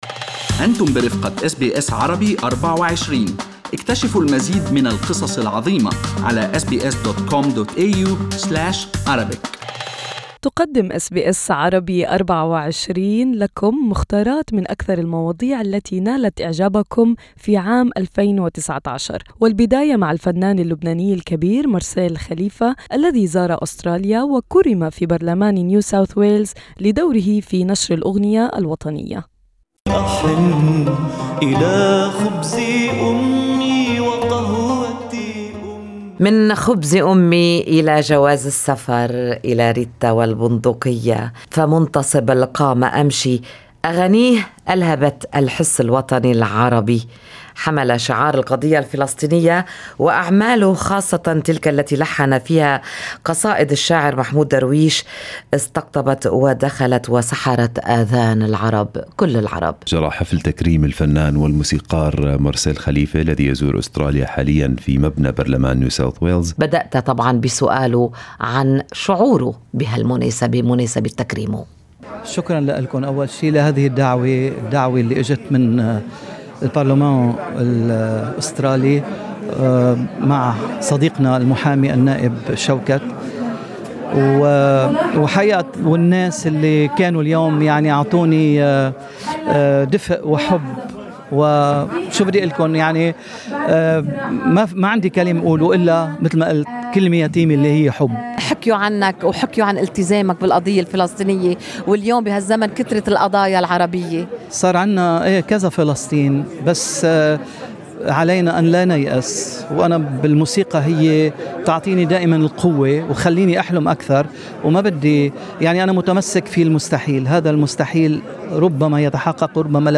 الأكثر استماعا 2019: لقاء حصري مع الفنان مارسيل خليفة من أستراليا
أبرز ما جاء في حفل تكريم الموسيقار مارسيل خليفة في برلمان نيو ساوث ويلز بدعوة من المعارضة العمالية.